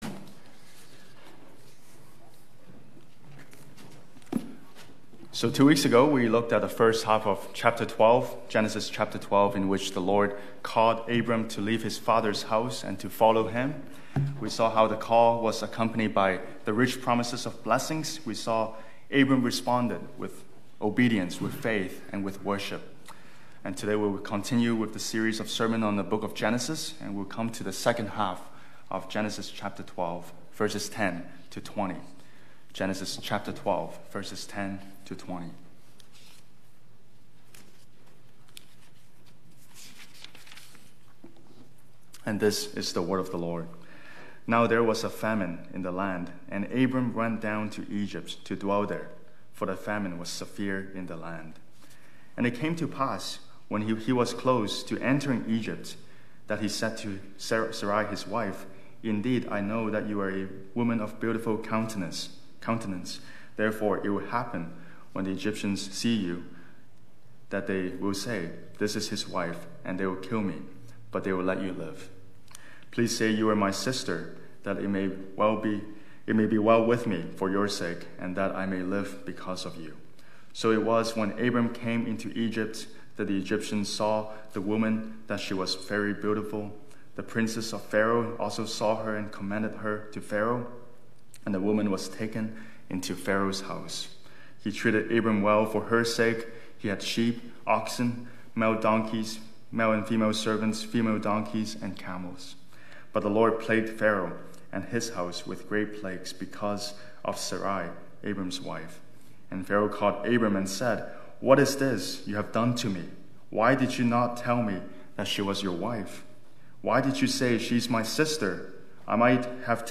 Passage: Genesis 12:10-20 Service Type: Sunday Morning